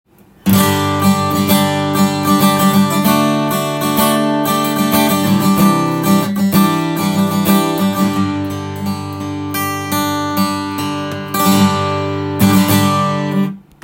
木が乾燥していて良い音がします。
試しに弾いてみました
コードストロークもアルペジオも響きがあっていいですね！